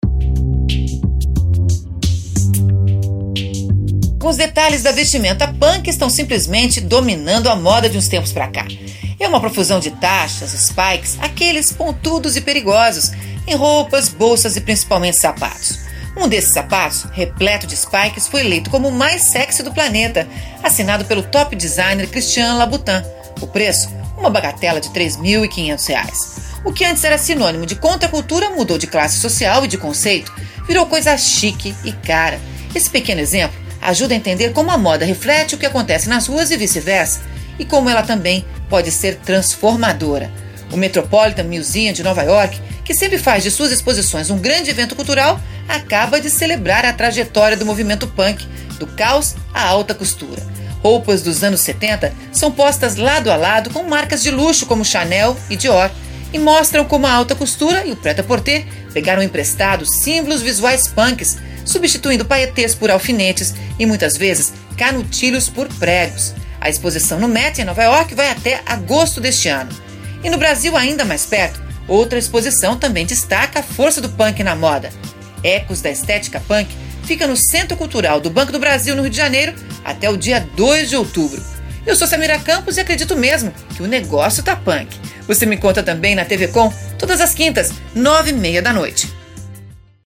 na rádio Itapema FM - SC